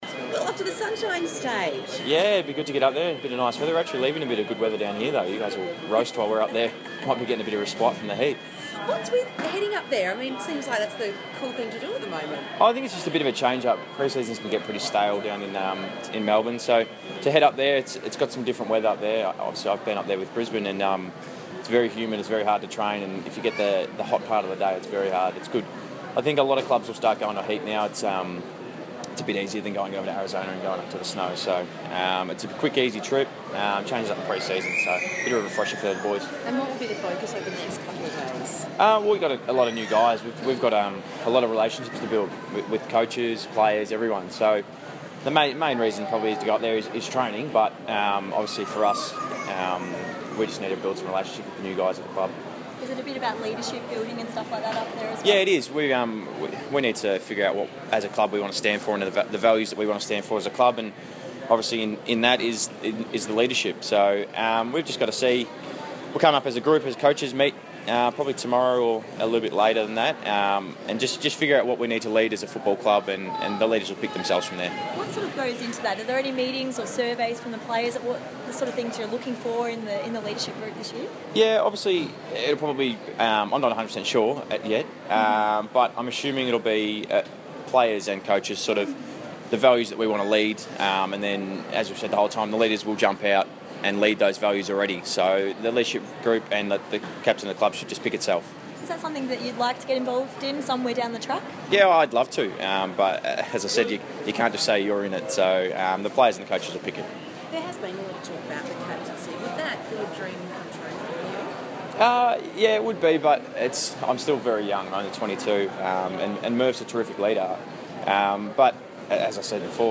Sam Docherty press conference - December 14
Carlton defender Sam Docherty spoke to the media at Melbourne Airport ahead of the team's pre-season training camp on the Gold Coast.